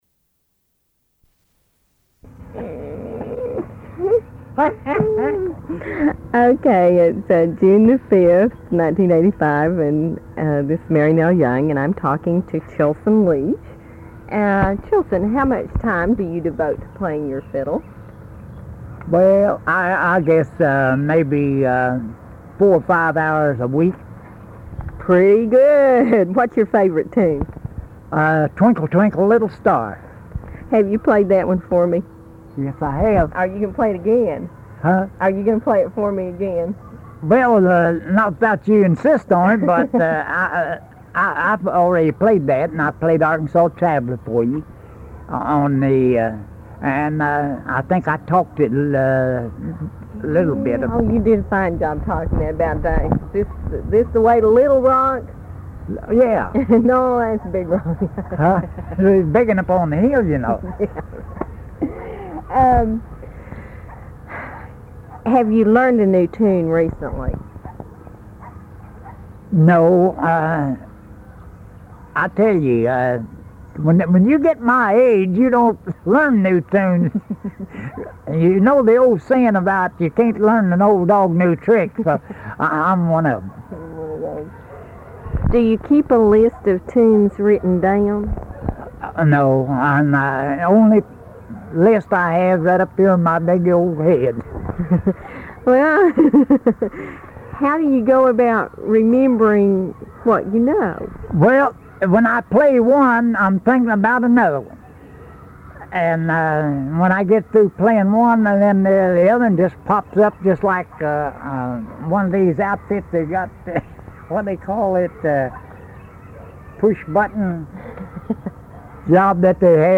Vintage Fiddling in Eastern Kentucky